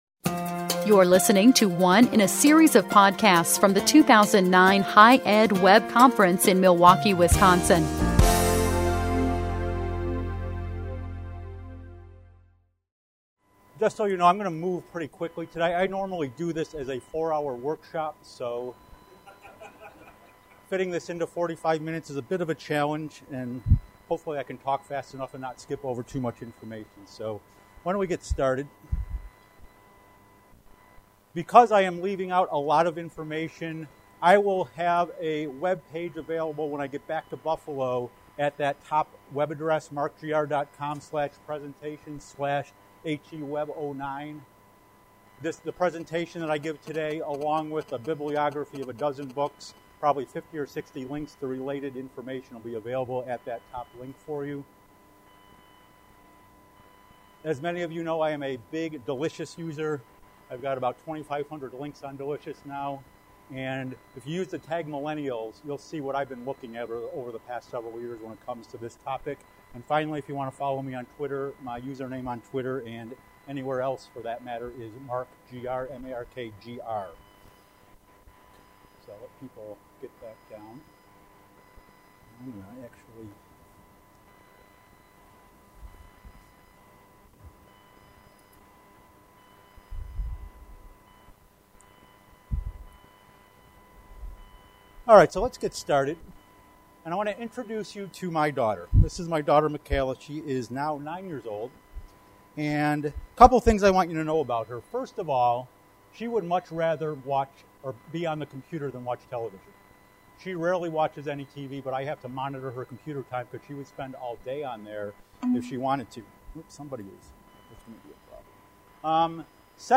Session Details - HighEdWeb 2009 Conference: Open + Connected